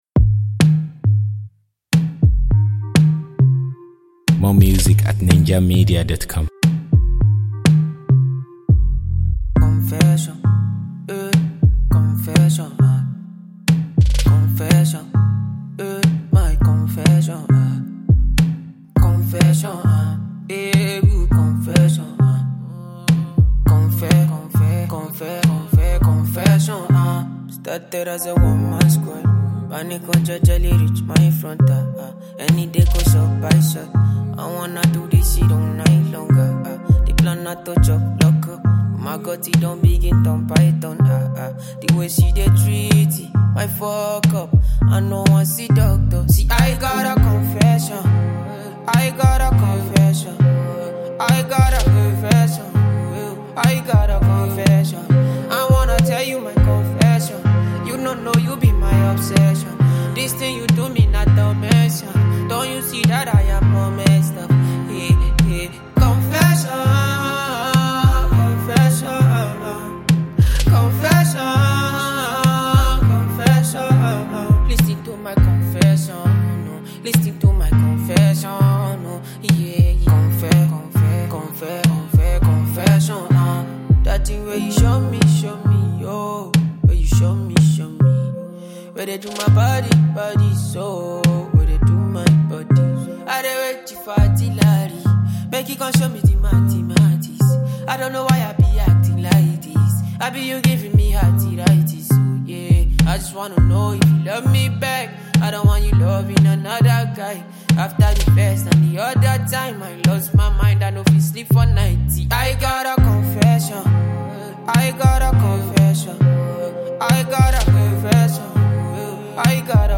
Afro-fusion artiste
silky-voiced